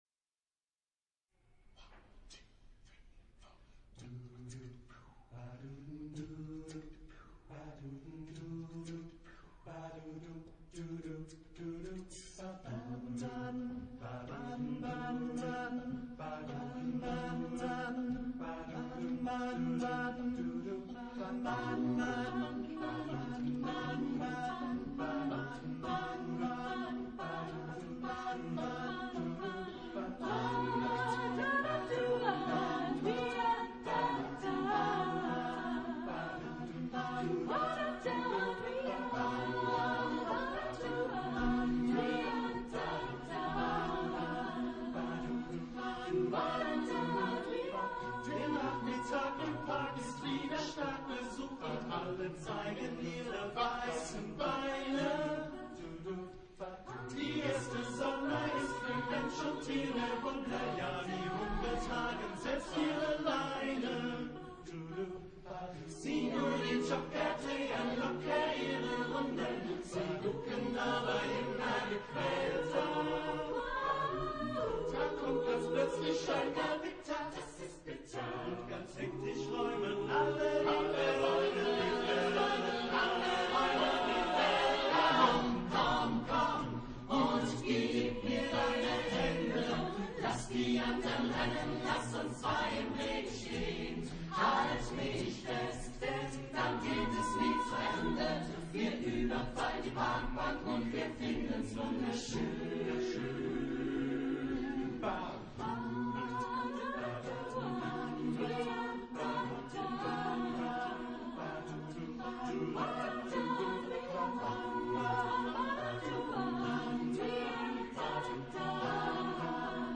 Genre-Style-Forme : Rock ; Variété ; Profane
Type de choeur : SATB  (4 voix mixtes )
Tonalité : si bémol majeur